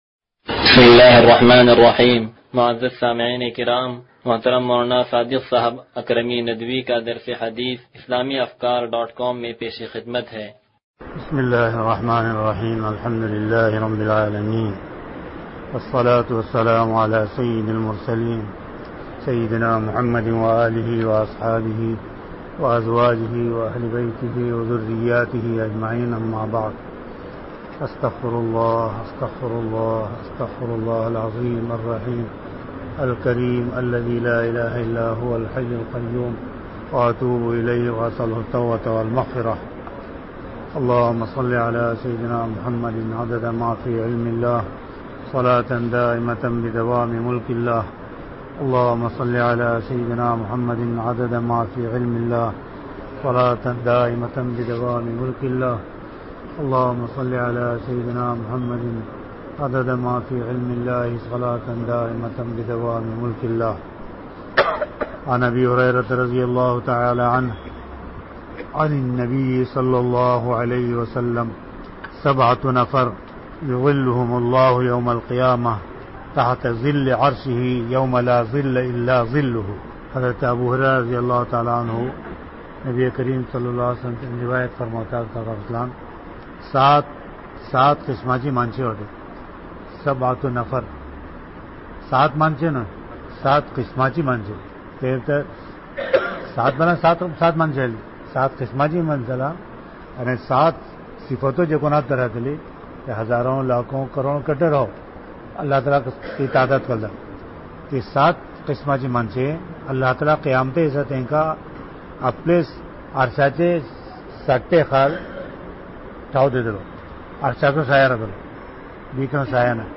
درس حدیث نمبر 0178